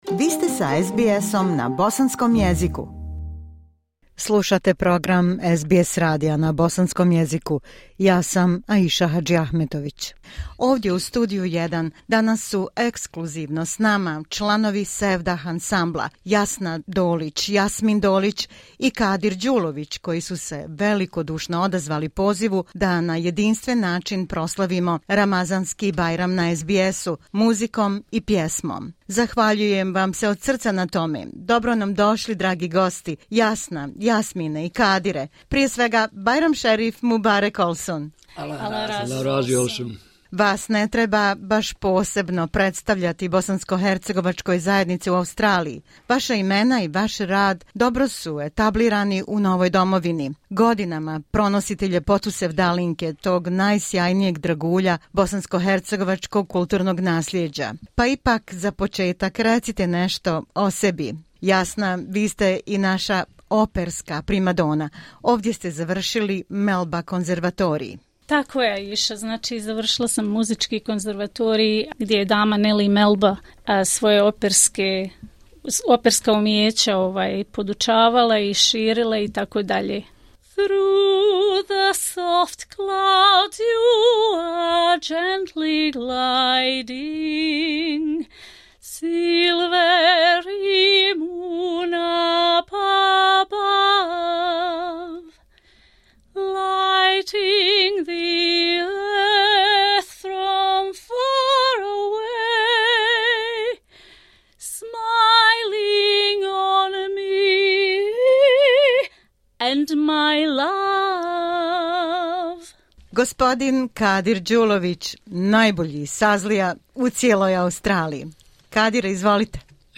live sevdalinka, accordion
saz